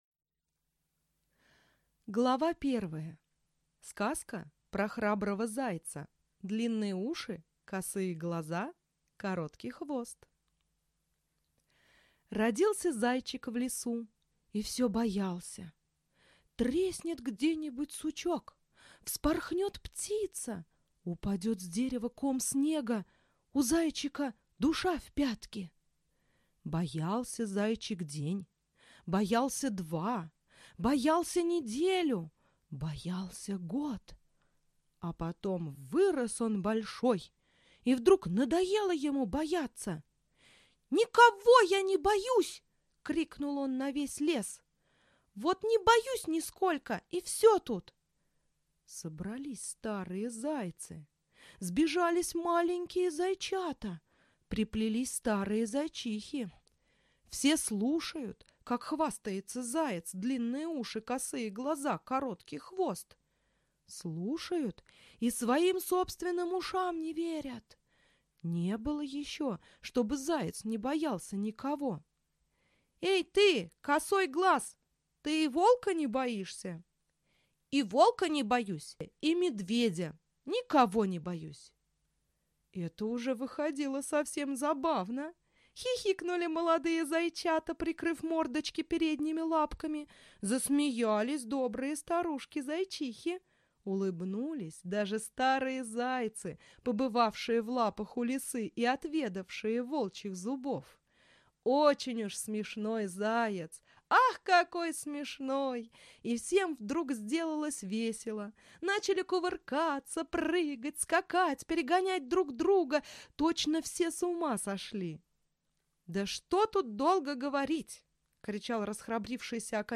Аудиокнига Аленушкины сказки | Библиотека аудиокниг